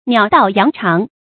發音讀音